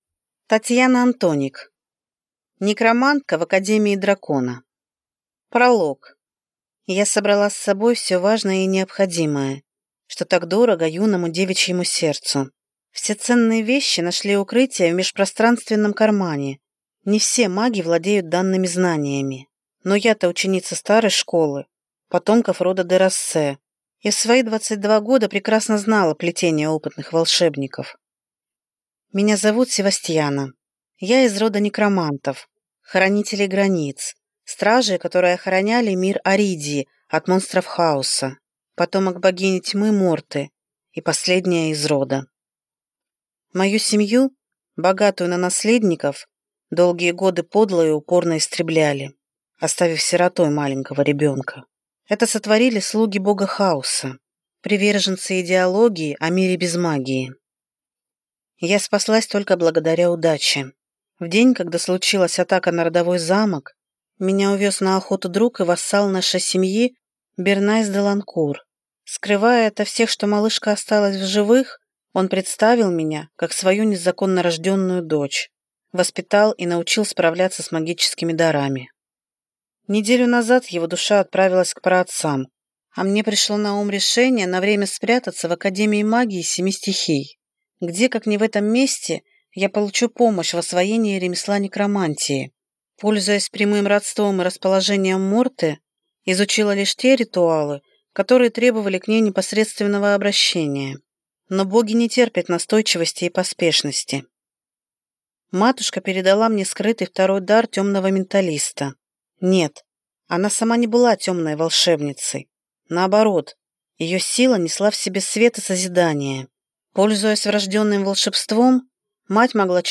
Аудиокнига Некромантка в академии дракона | Библиотека аудиокниг